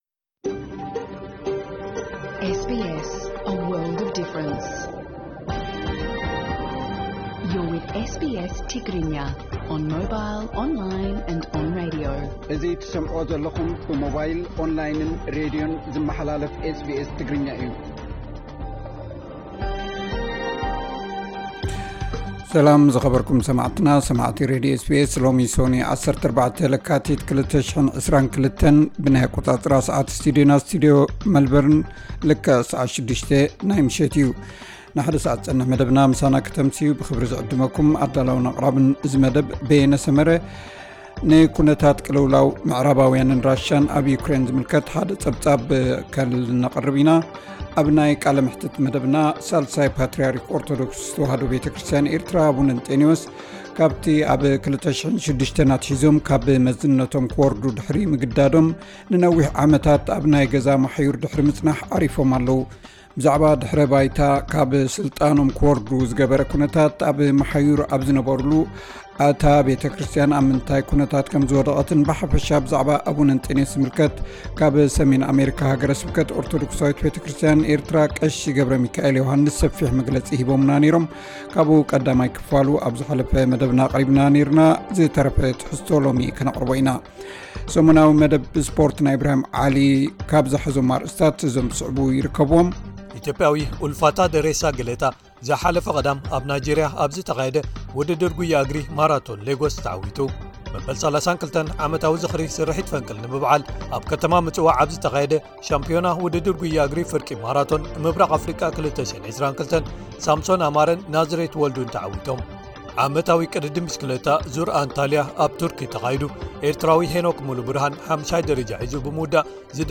ዕለታዊ ዜና SBS ትግርኛ (14 ለካቲት 2022)